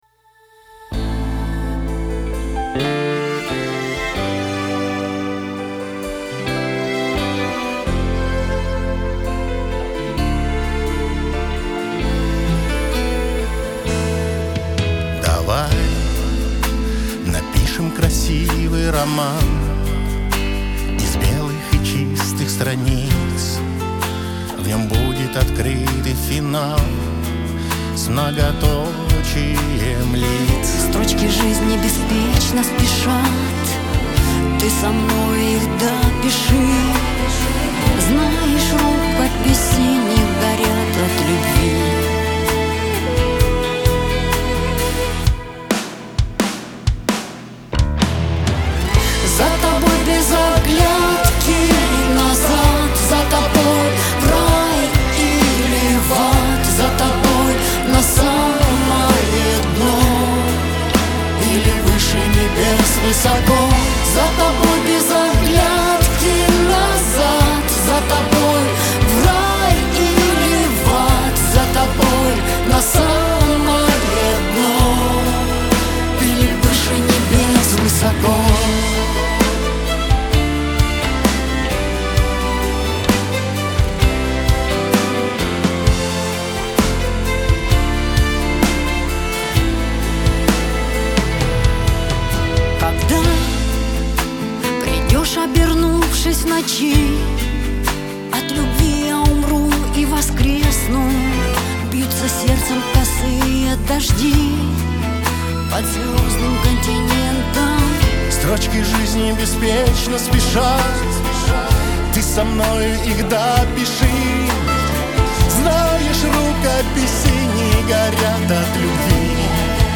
эстрада
дуэт , диско